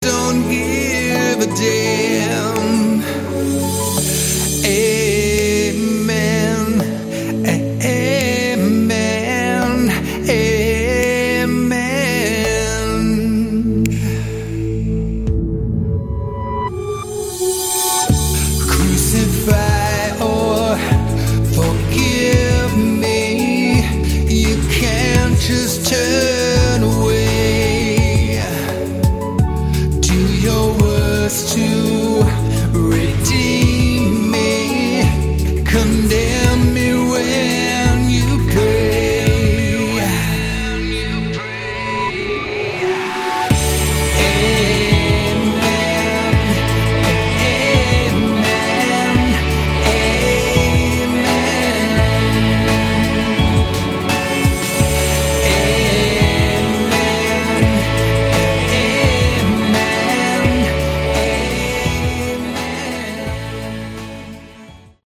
Category: Melodic Rock
lead & backing vocals